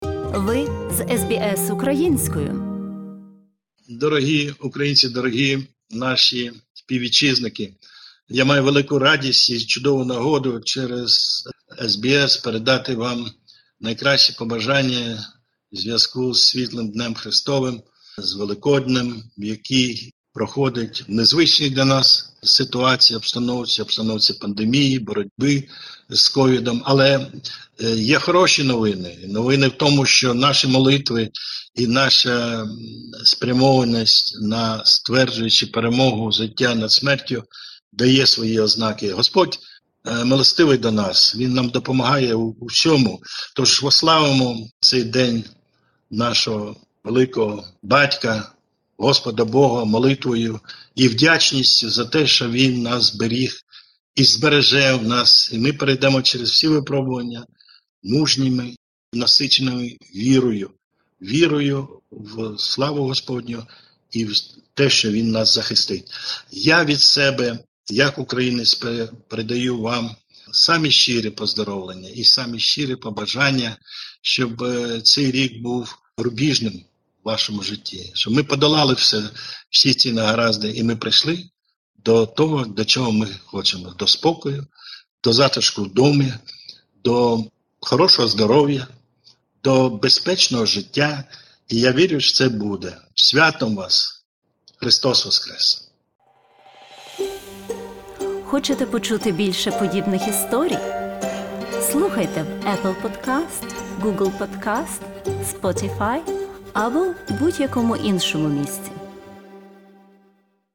Надзвичайний та повноважний посол України в Австралії доктор Микола Кулініч привітав українців Австралії з великодніми святами.